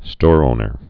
(stôrōnər)